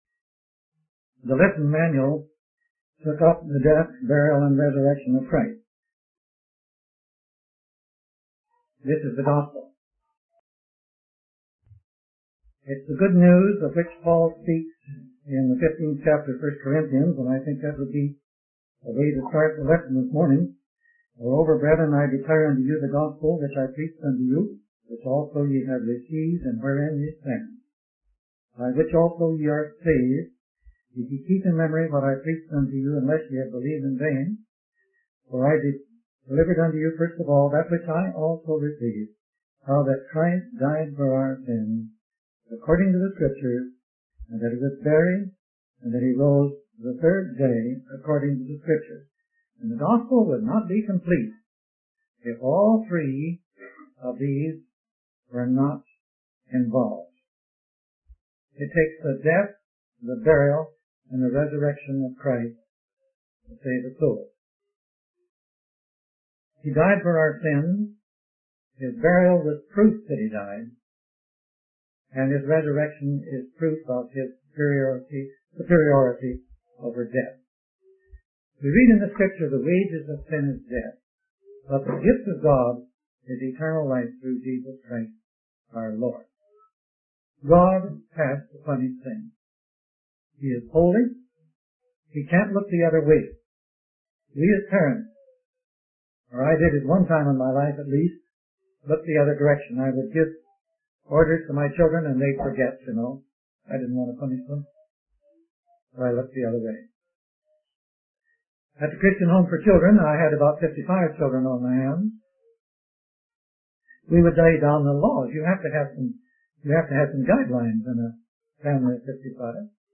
In this sermon, the preacher emphasizes the importance of the death, burial, and resurrection of Christ as the gospel.